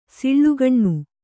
♪ sallēkhane